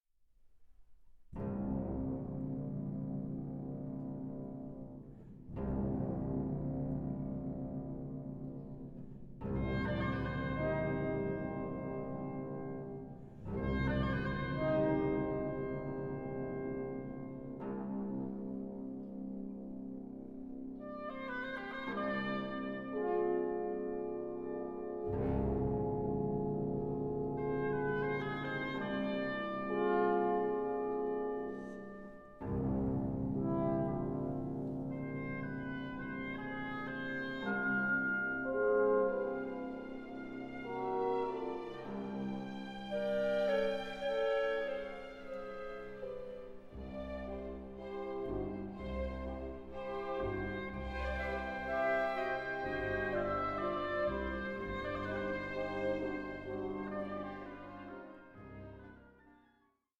(48/24, 88/24, 96/24) Stereo  14,99 Select
Residing somewhere between symphony and song cycle
and particularly in the contemplative last movement